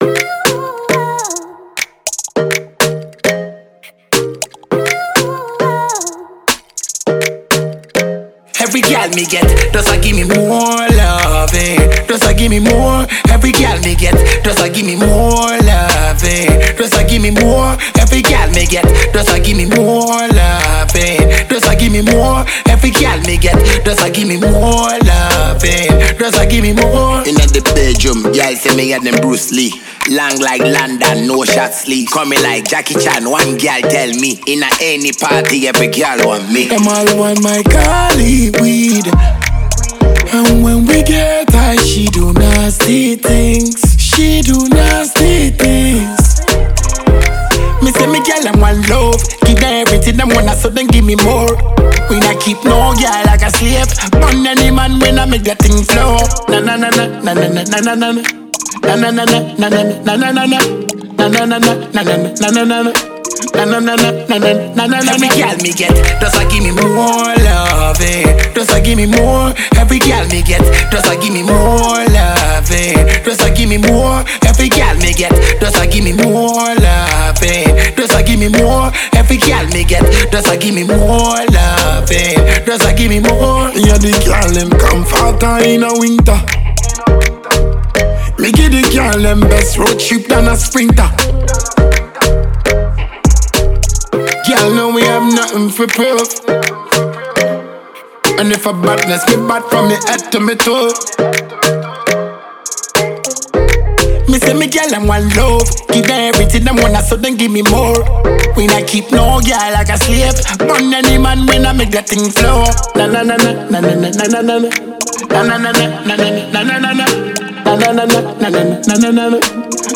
dancehall song